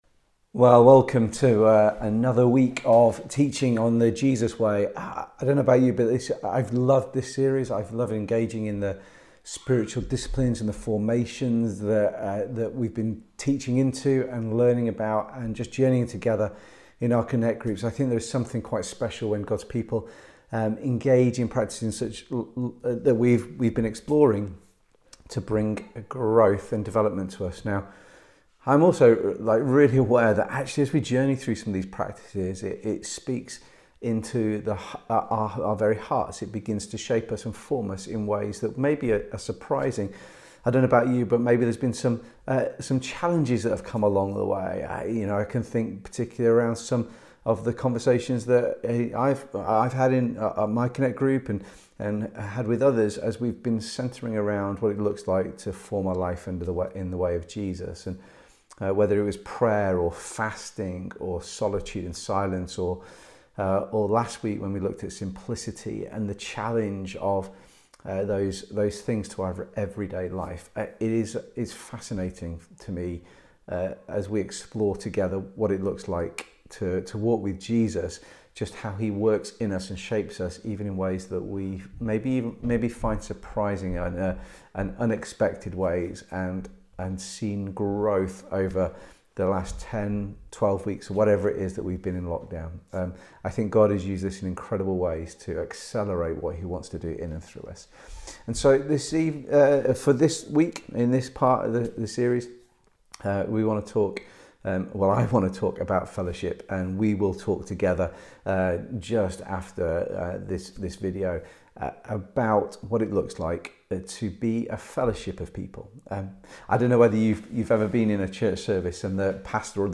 Listen to this weeks connect group teaching on Community.